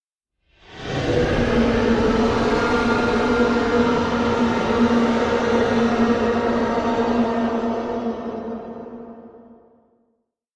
Sound Buttons: Sound Buttons View : Dragon's Roar
dragon-1.mp3